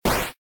flame.ogg